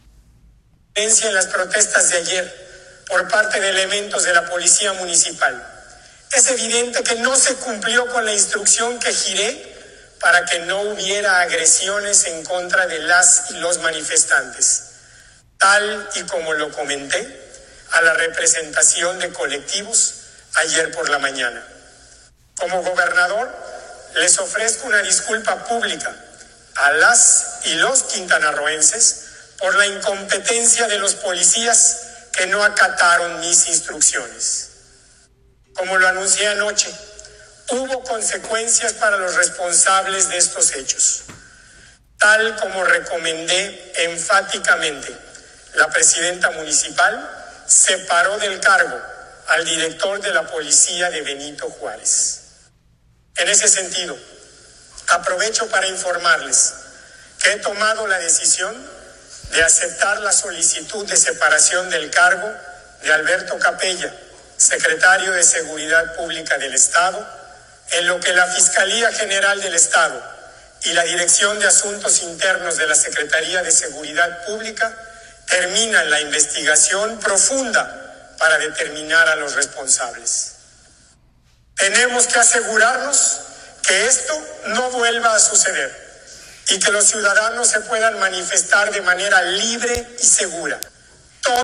CHETUMAL, Quintana Roo.- Carlos Joaquín González, gobernador de Quintana Roo, informó en una transmisión especial en sus cuentas en redes sociales que Alberto Capella Ibarra, secretario de Seguridad Pública, solicitó su separación temporal del cargo.
En su mensaje a la ciudadanía, el jefe del Ejecutivo quintanarroense señaló que aceptó la solicitud de Alberto Capella.